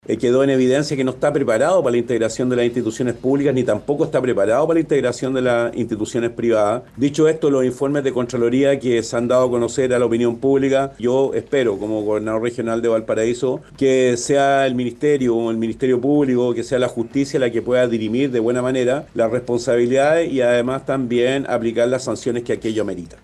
En entrevista con Radio Bío Bío, Mundaca también lanzó sus dardos contra la burocracia en el manejo de la emergencia, pidiendo la determinación de responsabilidades por esta situación y que se apliquen las sanciones correspondientes.